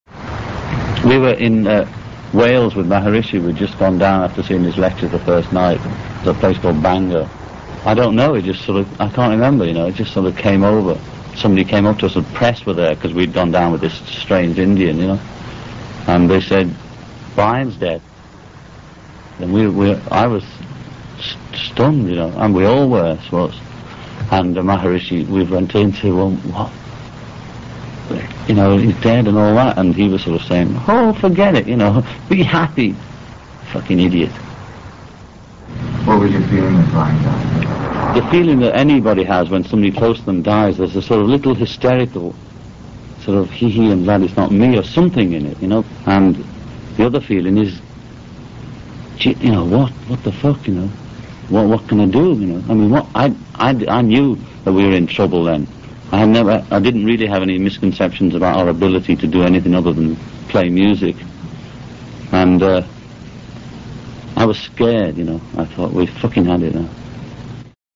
[SOUND] Here, John speaks frankly about the loss of Brian. WARNING! This sound clip includes crude language!